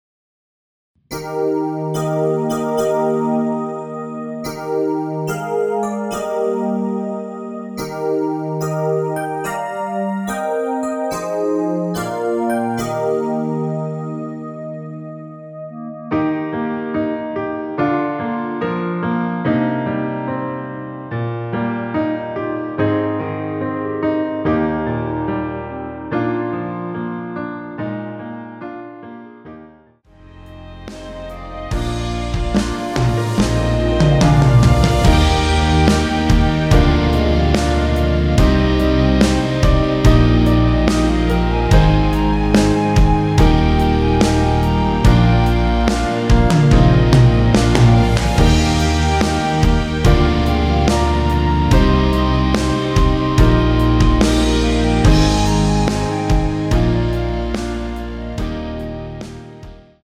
원키에서(-1)내린 멜로디 포함된 MR입니다.
Eb
멜로디 MR이라고 합니다.
앞부분30초, 뒷부분30초씩 편집해서 올려 드리고 있습니다.
중간에 음이 끈어지고 다시 나오는 이유는